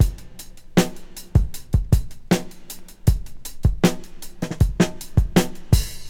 • 79 Bpm Drum Loop Sample F# Key.wav
Free drum groove - kick tuned to the F# note. Loudest frequency: 972Hz
79-bpm-drum-loop-sample-f-sharp-key-bKX.wav